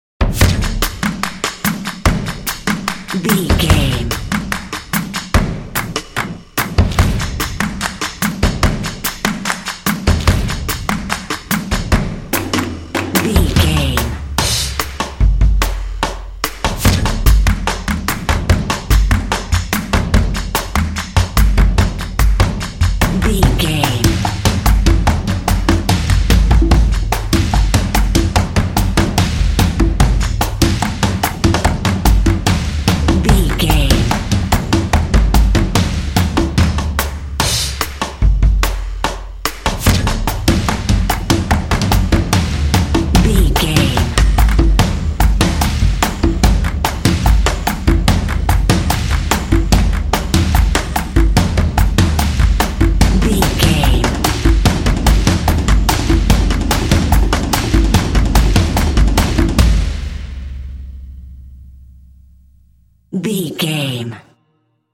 Epic / Action
Atonal
tension
drums
drumline